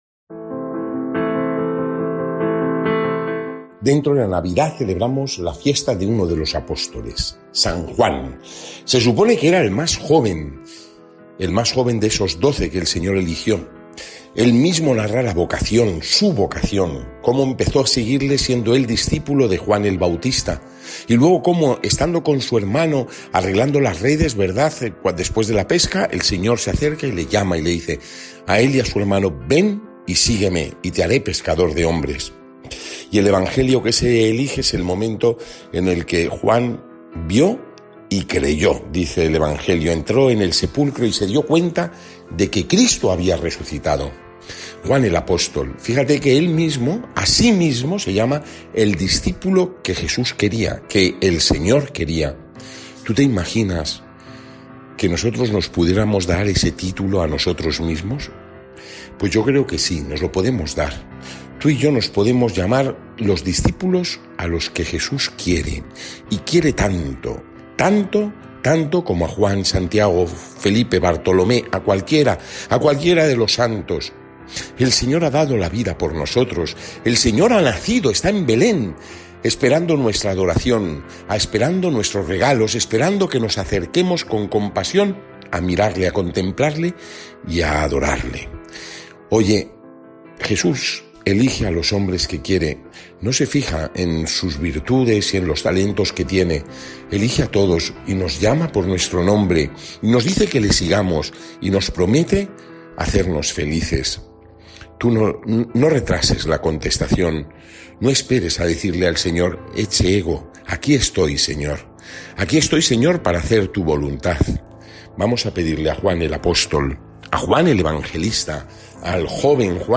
Evangelio